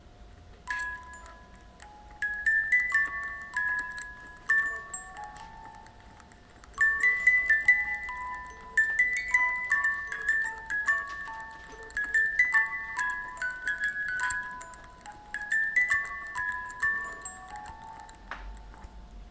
Music box in desk draw